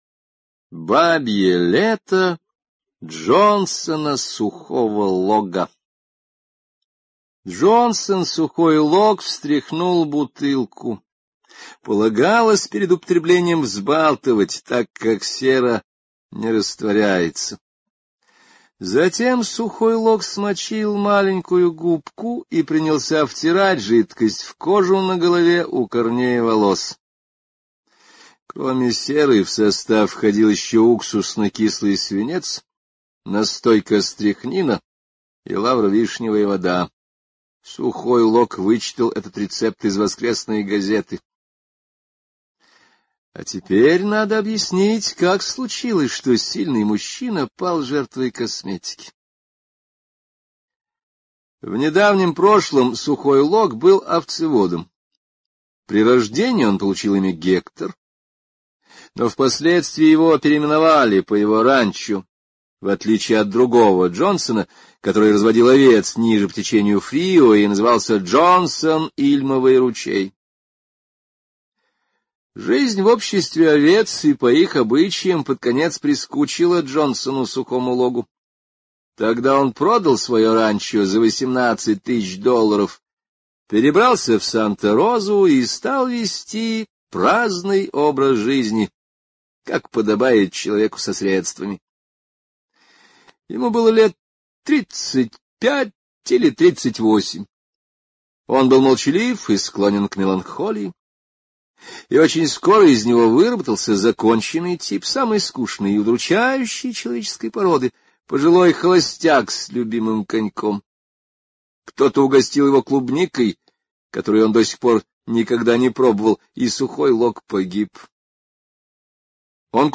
Бабье лето Джонсона Сухого Лога — слушать аудиосказку Генри О бесплатно онлайн